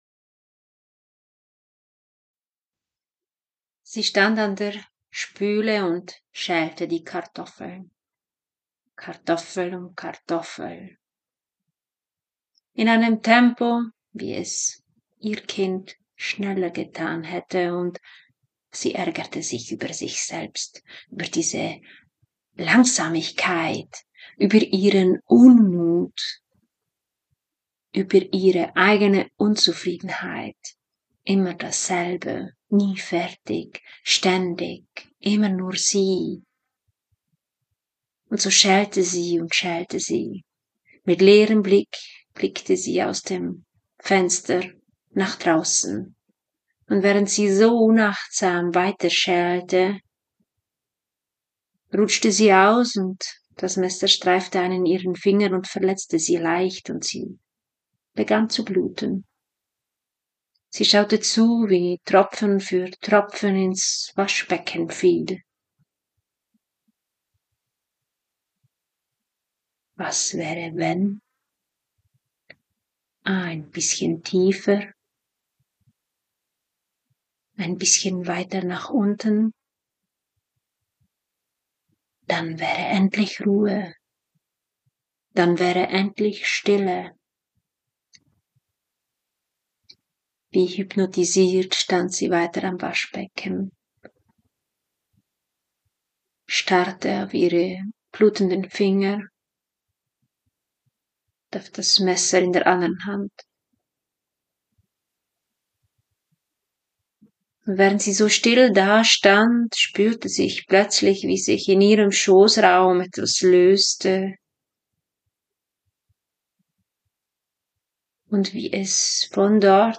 Die Erzählerin spricht über das Gefühl, jeden Monat